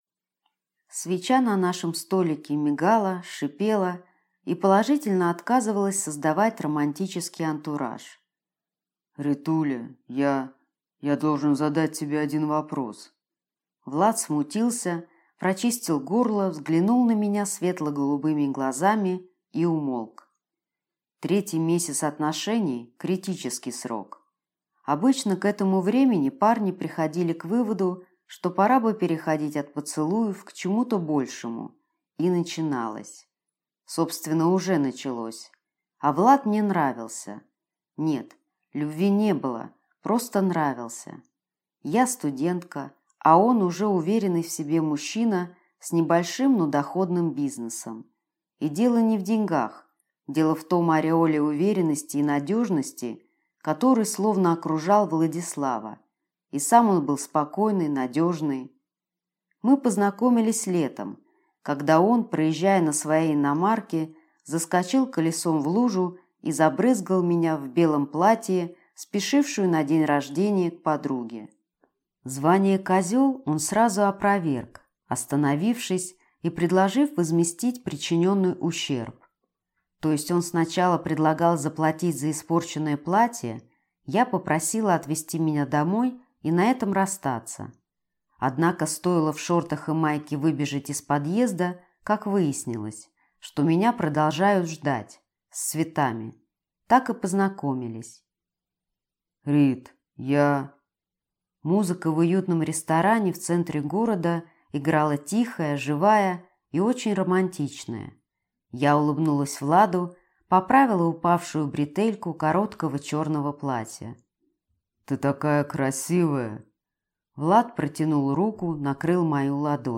Аудиокнига Все ведьмы – рыжие | Библиотека аудиокниг
Прослушать и бесплатно скачать фрагмент аудиокниги